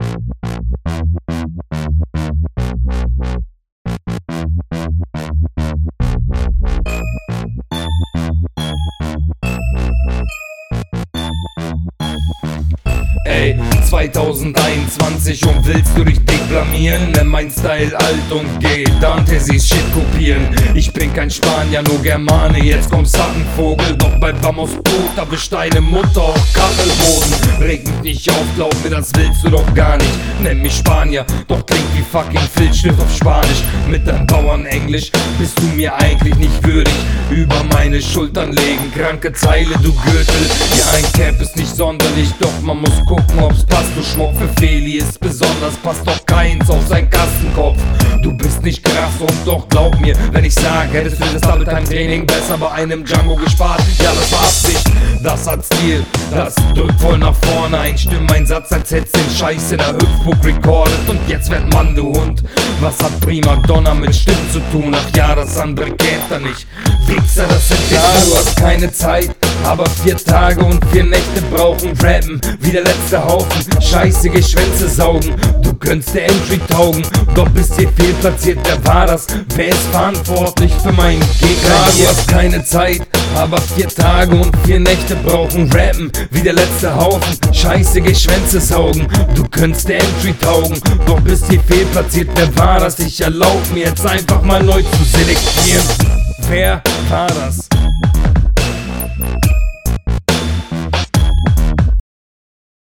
Bei mir gabs leider Verständlichkeitsprobleme bei der "Kachelboden" zeile.
Deine Runde und dein Flow ist auch hier unerwartet Nice.